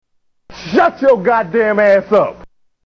Televangelist